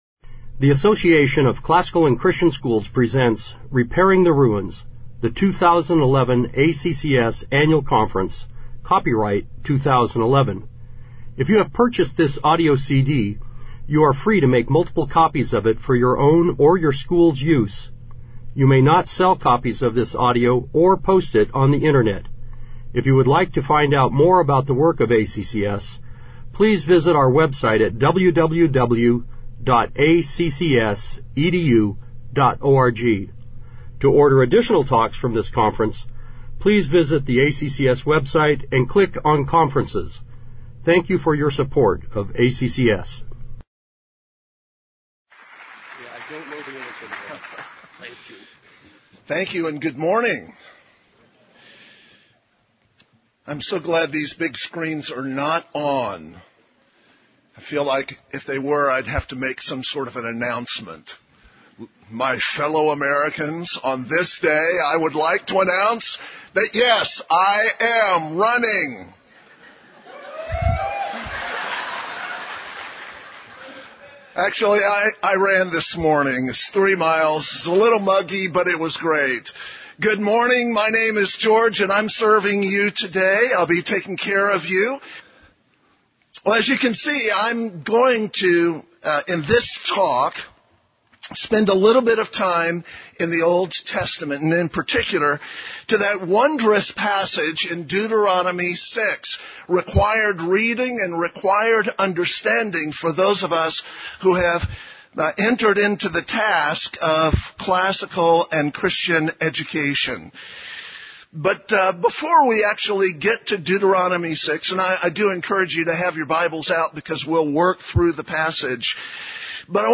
2011 Plenary Talk | 0:43:27 | All Grade Levels, General Classroom
The Association of Classical & Christian Schools presents Repairing the Ruins, the ACCS annual conference, copyright ACCS.